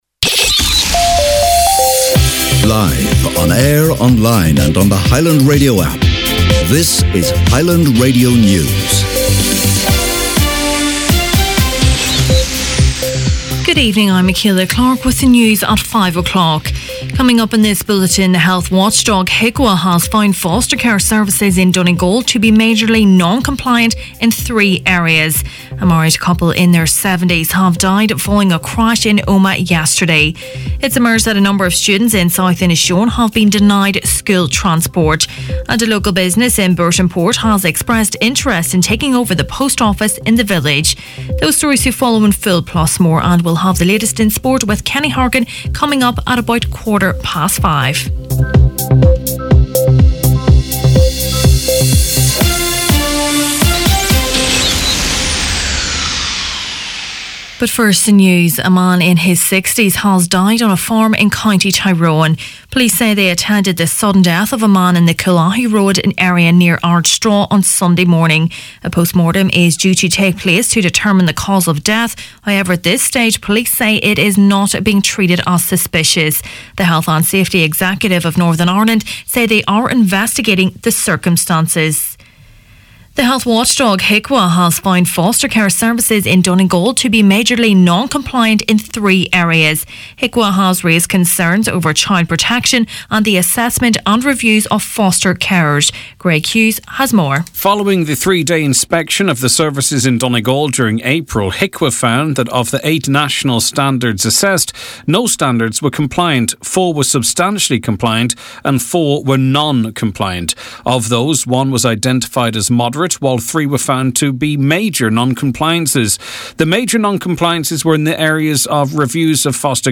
Main Evening News, Sport and Obituaries Tuesday September 4th